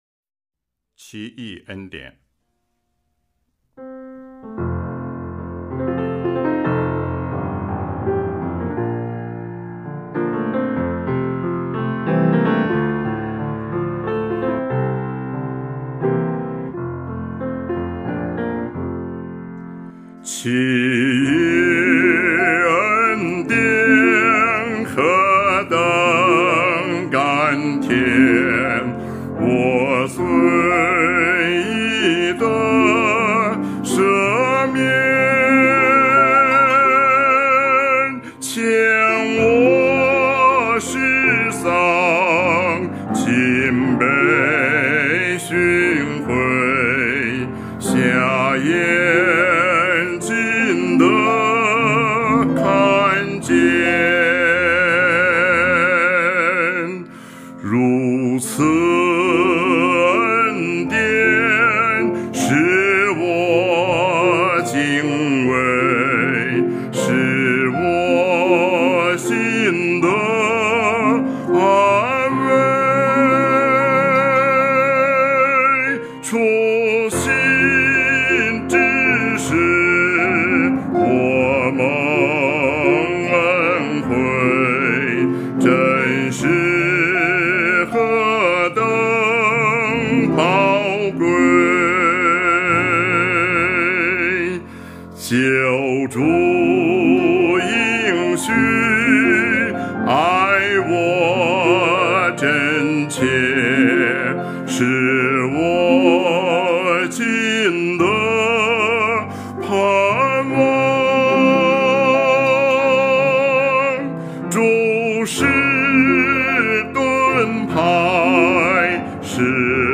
赞美诗《奇异恩典》